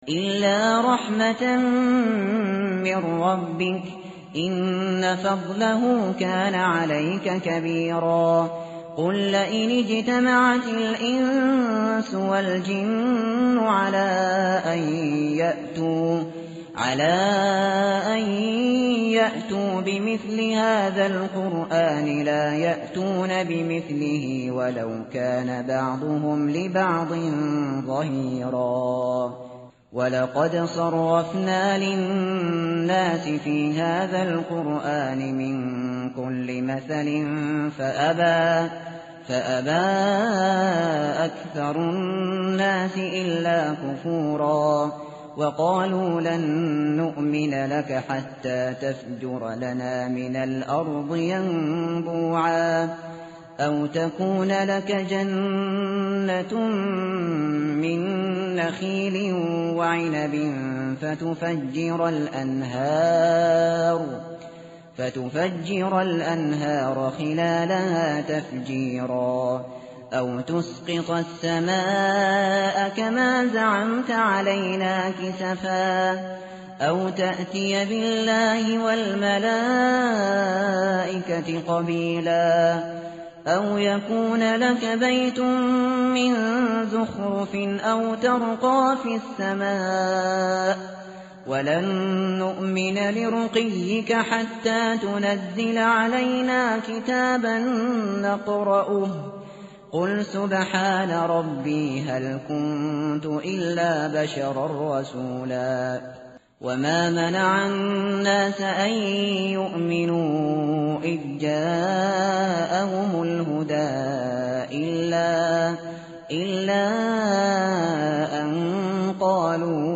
متن قرآن همراه باتلاوت قرآن و ترجمه
tartil_shateri_page_291.mp3